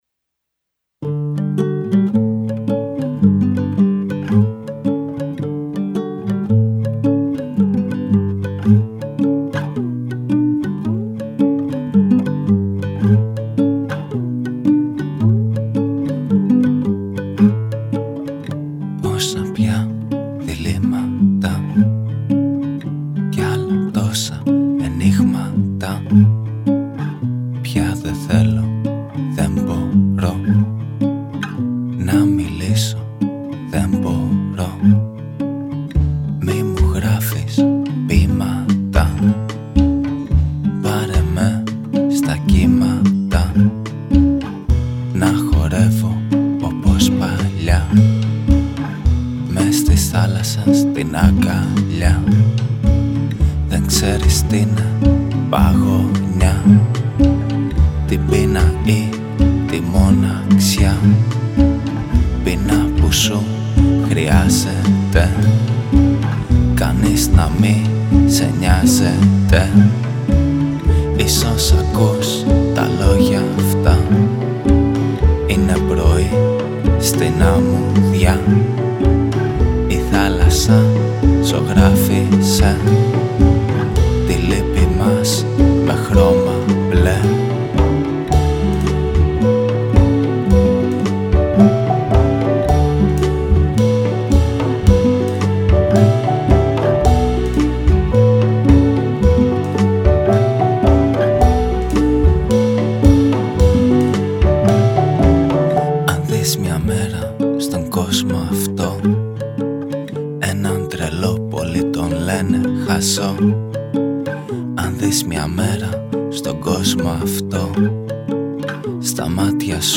Τα τύμπανα
πιάνο